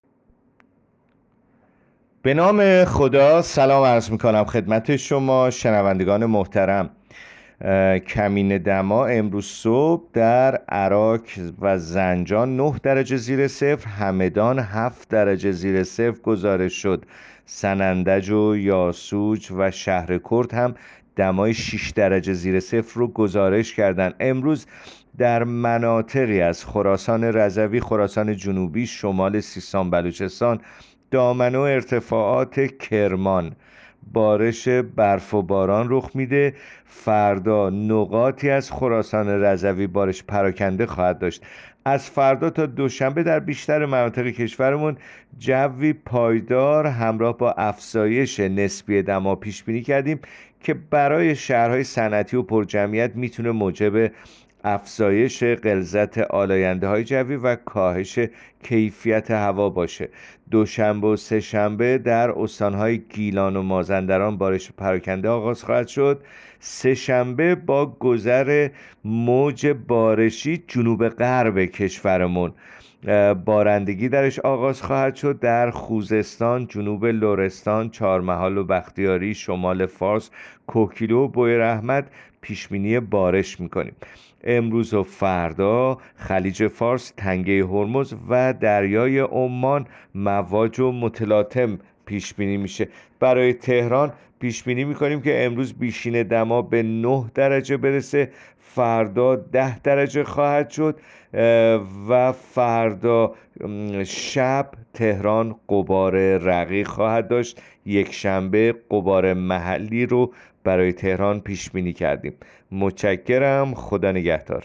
گزارش رادیو اینترنتی پایگاه‌ خبری از آخرین وضعیت آب‌وهوای ۱۴ دی؛